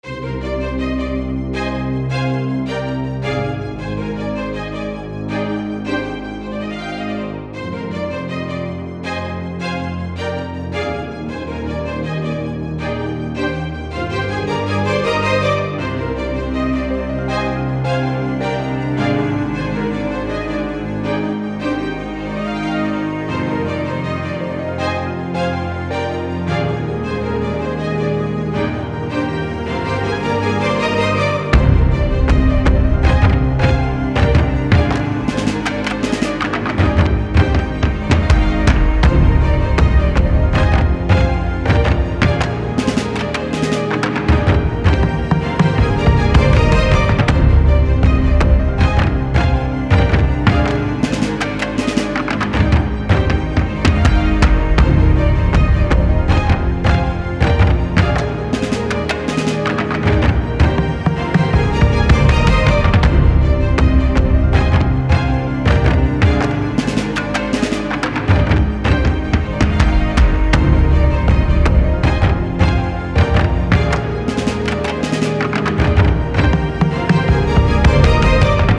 Dramatisches Hiphop Sample
Es ist ein 5 1/4 / 4 Takt.
Ansonsten liegen die Drums auf den Akkorden der Violinen.
Der eigentliche Loop ist nur 16 Sekunden lang. Jetzt ist der Aufbau: Intro - alle Spuren - 3 Mal alle Spuren+Drums Das Klavier ist in der Mitte hinten positioniert (Panning+Reverb).